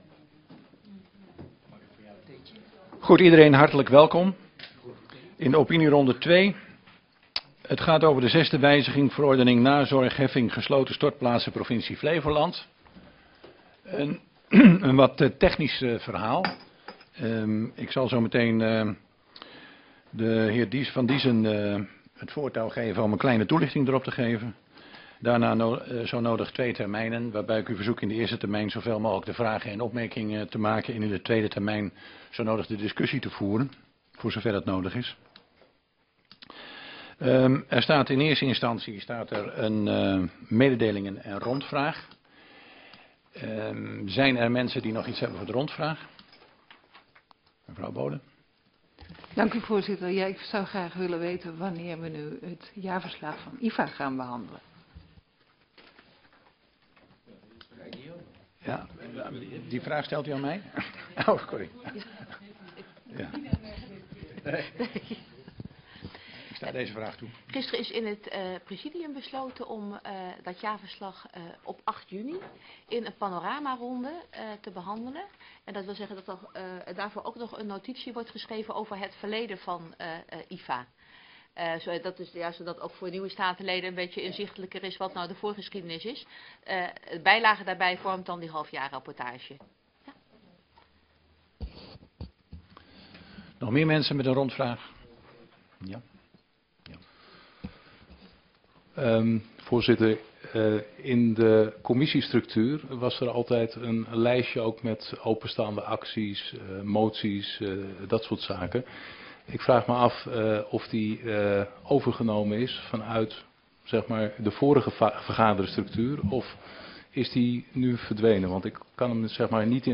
Locatie: Statenzaal